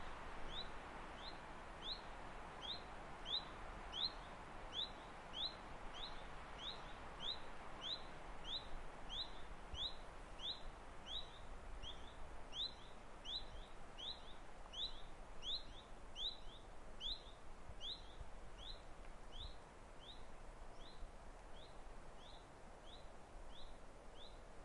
描述：在Pembrokeshire森林的春日，与Moto G的未经编辑的现场录音。
标签： 鸟鸣声 氛围 春天 性质 现场录音
声道立体声